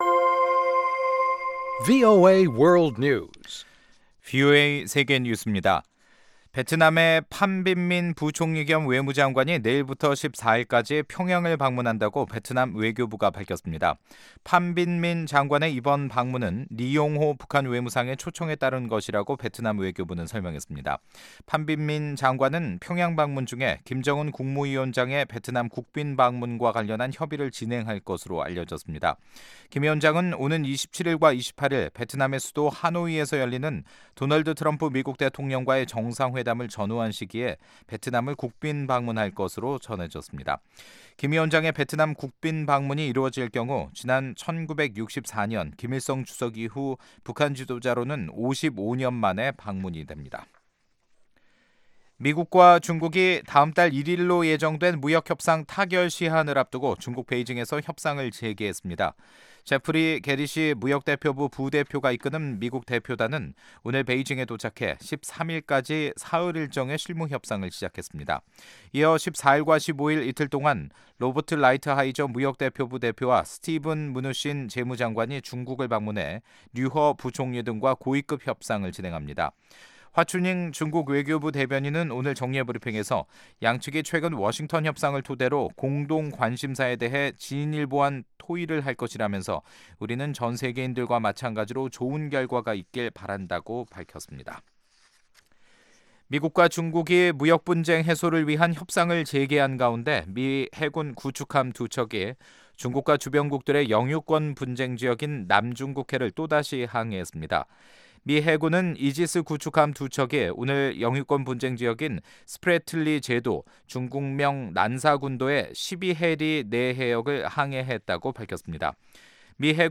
생방송 여기는 워싱턴입니다 2/11저녁